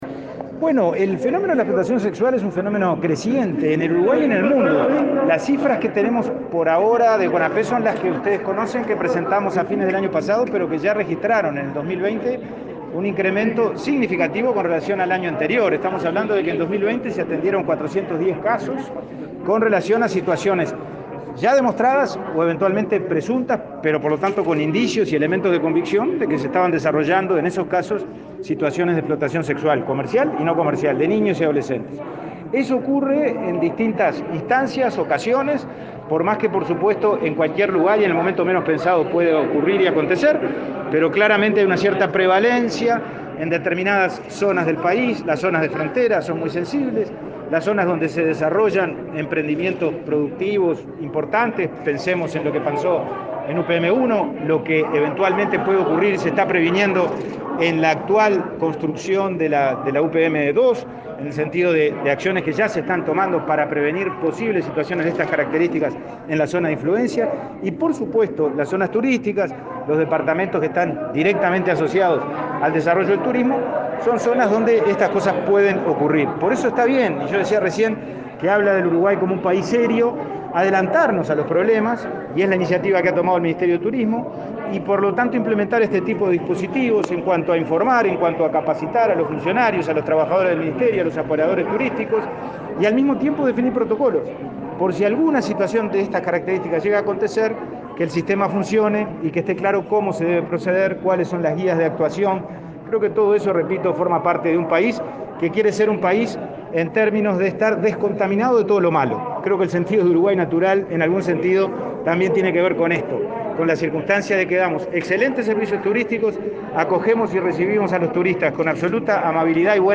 Declaraciones a la prensa del presidente del INAU, Pablo Abdala